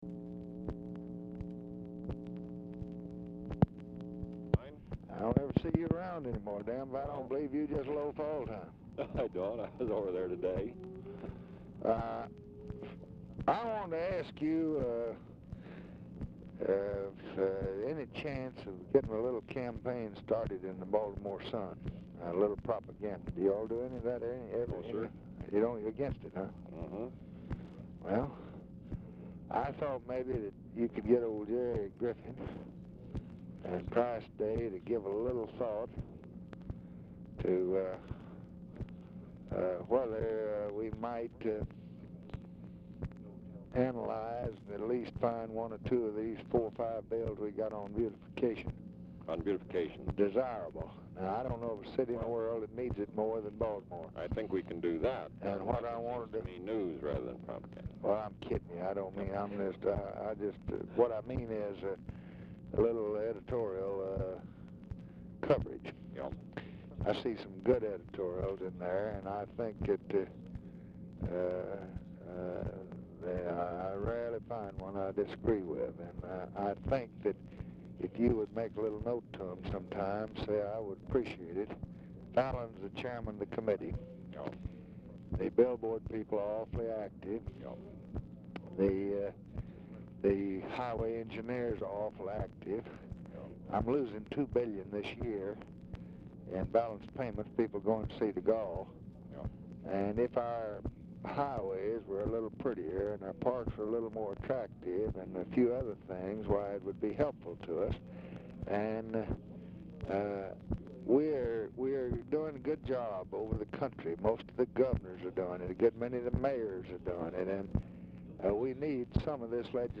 TV OR RADIO AUDIBLE IN BACKGROUND
Format Dictation belt
Specific Item Type Telephone conversation